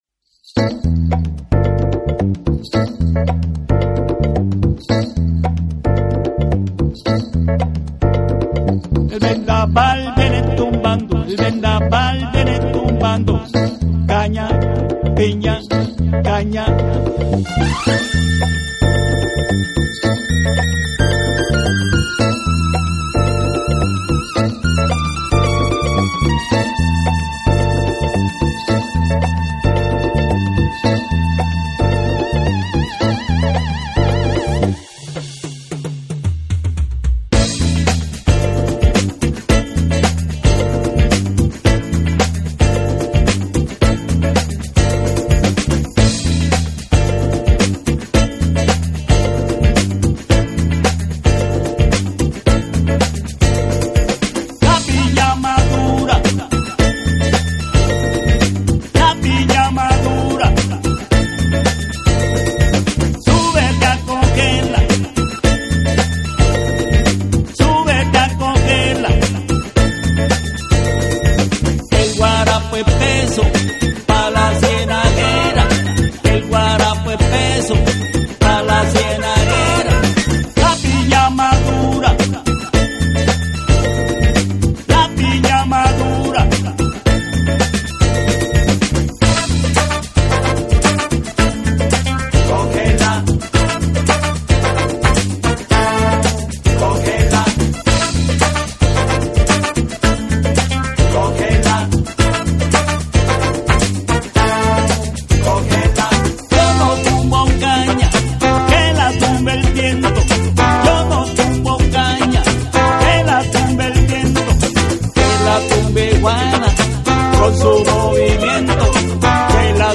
WORLD / BREAKBEATS / NEW RELEASE(新譜)